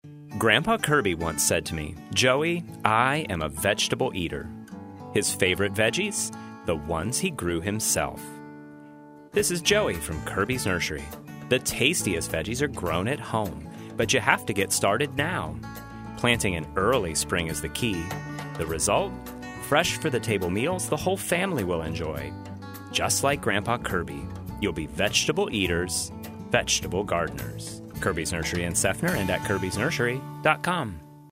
radio ad that you’ve probably heard while driving to work or carting the kids around.